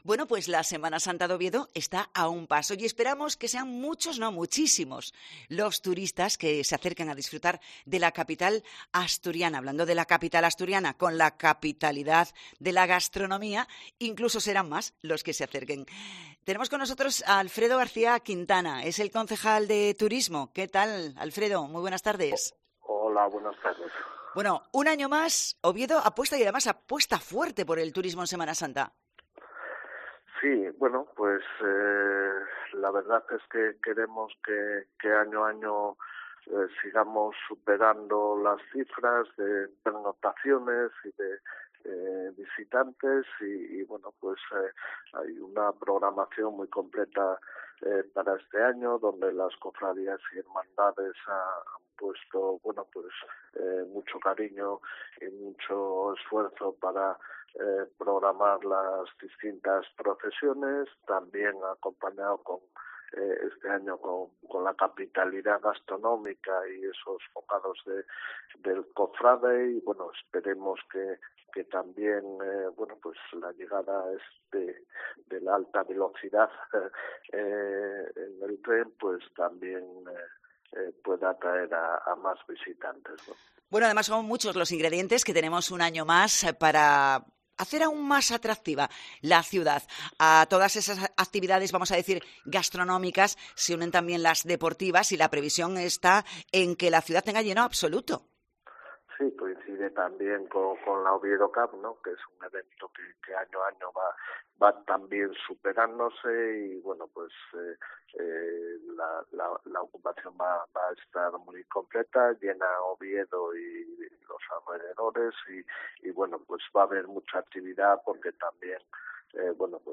Entrevista con Alfredo Quintana, concejal de Turismo de Oviedo